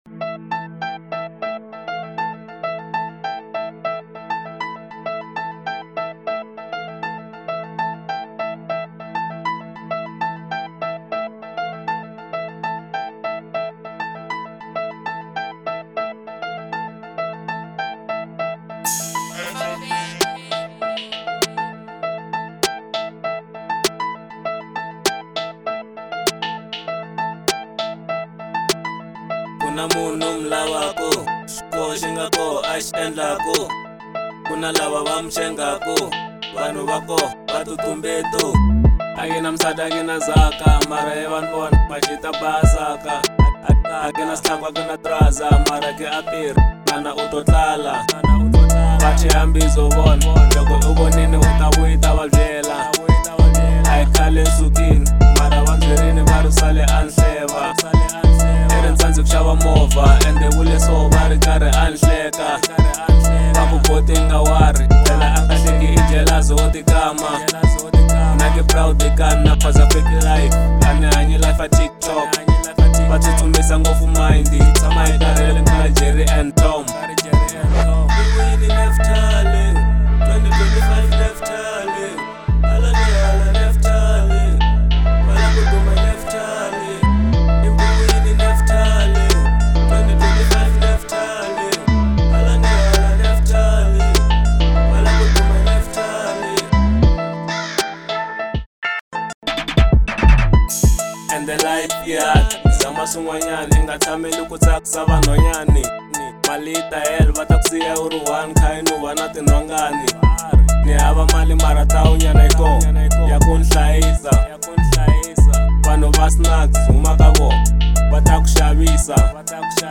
03:29 Genre : Hip Hop Size